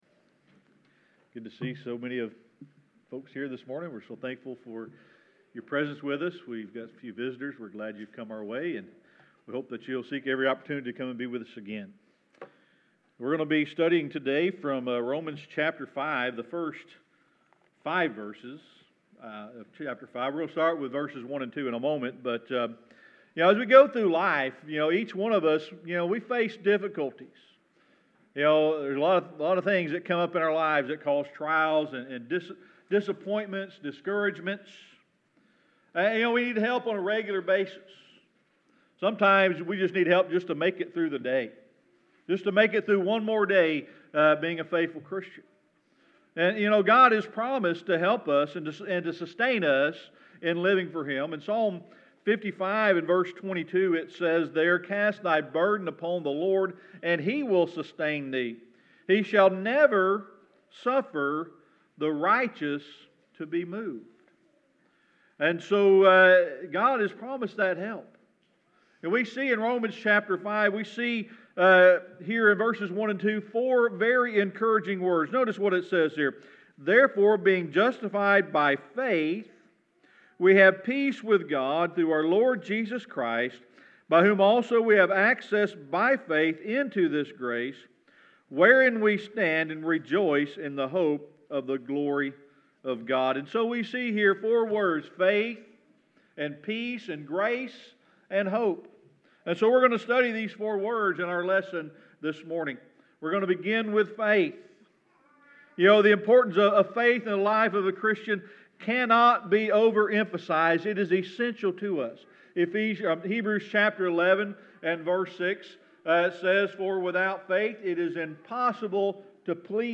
Series: Sermon Archives
Romans 5:1-5 Service Type: Sunday Morning Worship We're going to be studying today from Romans chapter 5